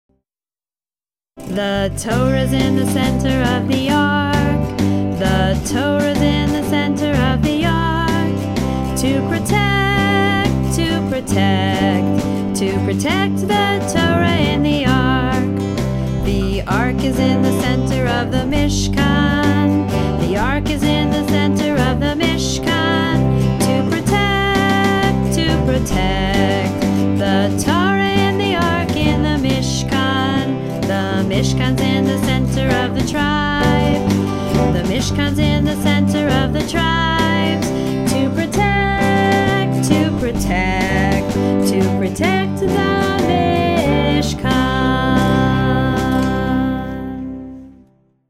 2) SONG:  (tune:  There’s a Hole in the Bottom of the Sea)